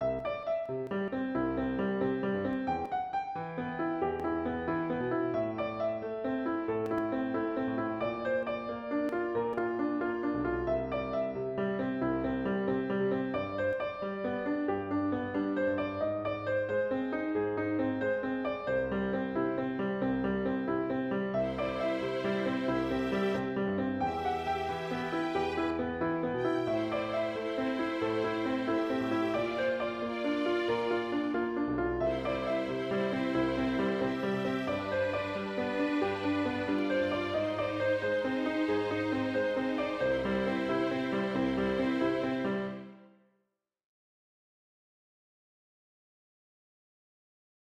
Für Ensemble
Ensemblemusik